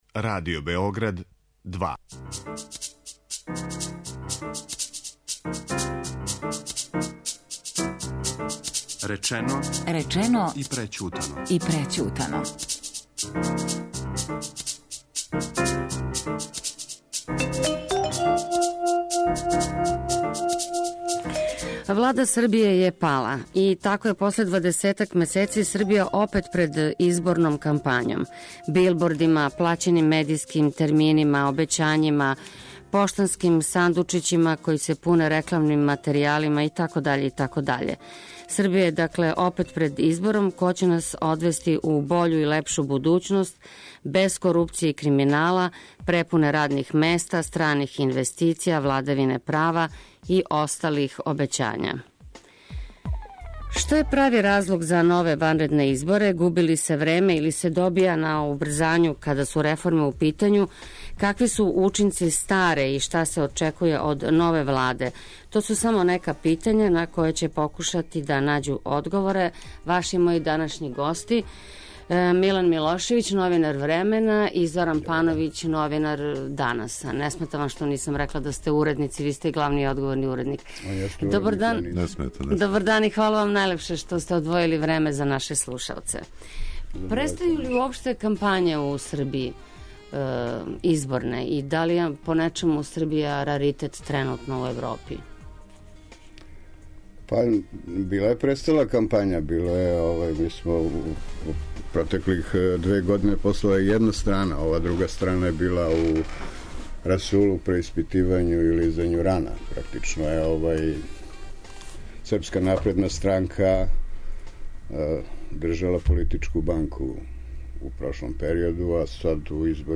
Учествују новинари